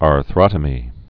(är-thrŏtə-mē)